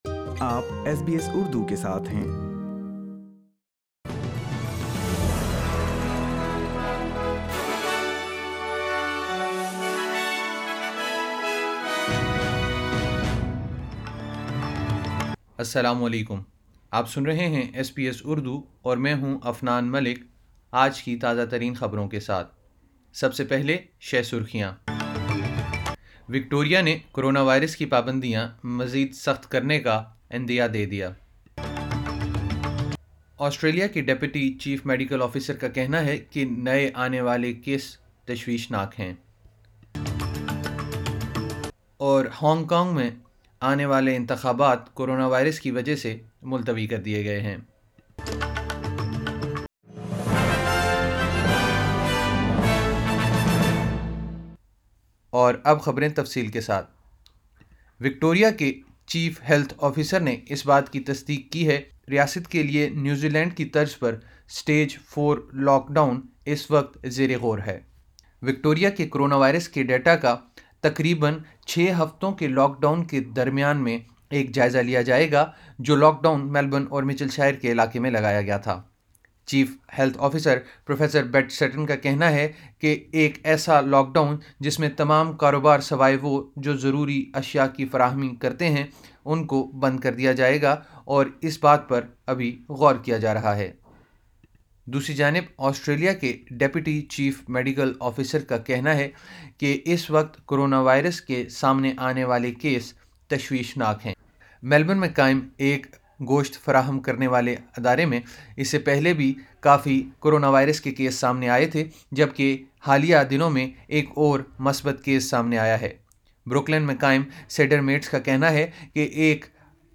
ایس بی ایس اردو خبریں 01 اگست 2020